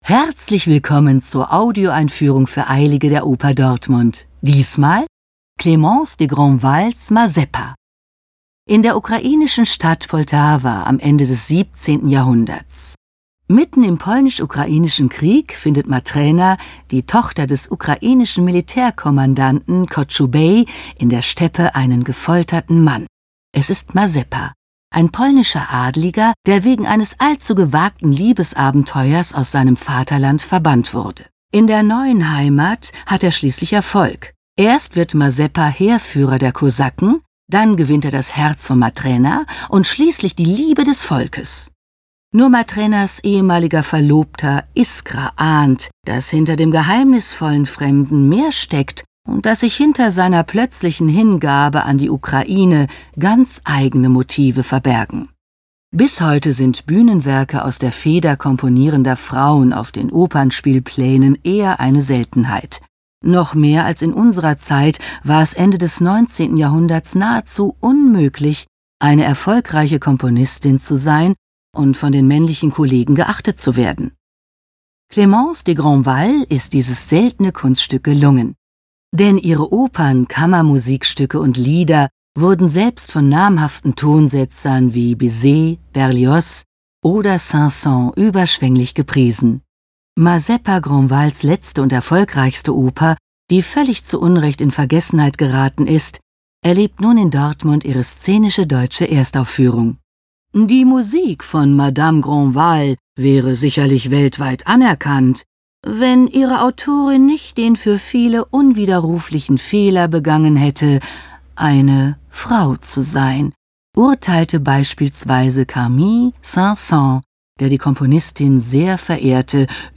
tdo_Audioeinfuehrung_Mazeppa.mp3